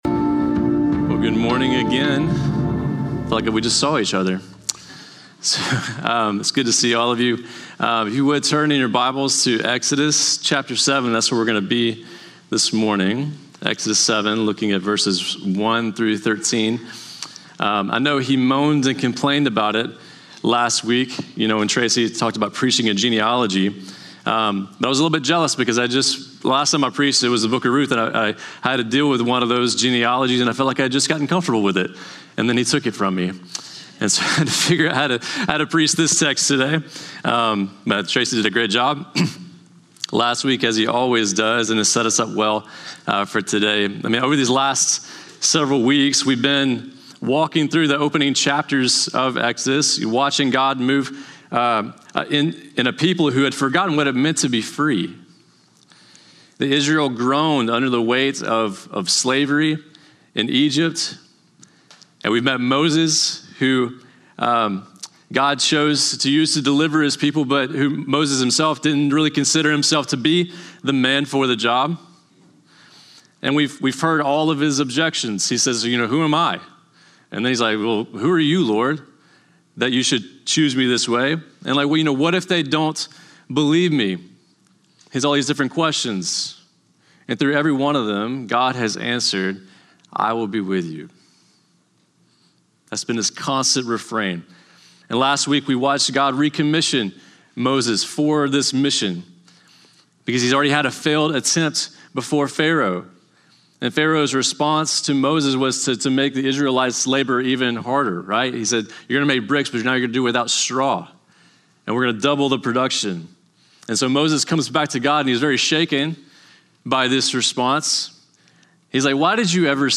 Norris Ferry Sermons Mar. 22, 2026 -- Exodus 7:1-13 Mar 22 2026 | 00:39:52 1x 00:00 / 00:39:52 Subscribe Share Spotify RSS Feed Share Link Embed